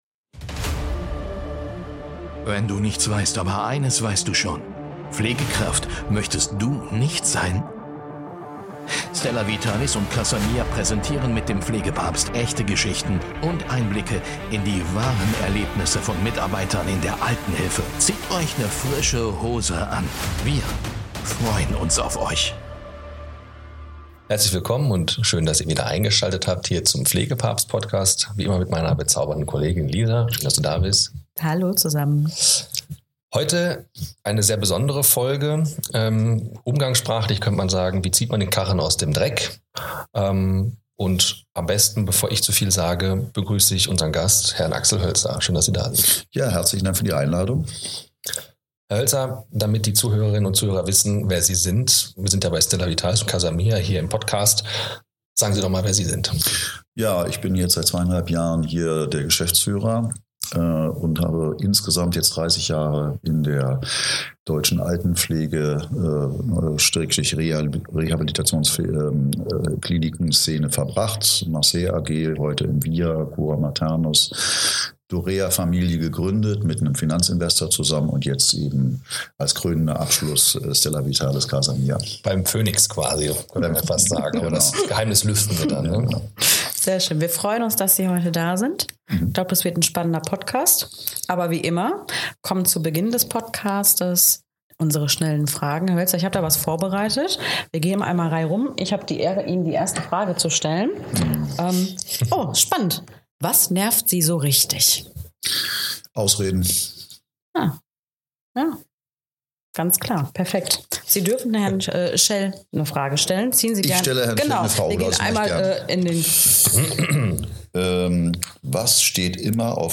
Wir sprechen über: • Die Kunst, als Kapitän im Sturm den Kurs zu halten • Den Aufbau moderner Führungsstrukturen und motivierter Teams • Die Chancen, die Digitalisierung, Ausbildung und Zuwanderung für die Pflege bieten • Den Wert einer Haltung, die nicht Probleme zählt, sondern Lösungen schafft Ein Gespräch über Mut, Unternehmertum, Zusammenhalt und den klaren Blick nach vorn – für eine Altenpflege, die auch in Zukunft stark bleibt.